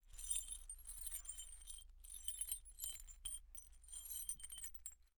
Metal_49.wav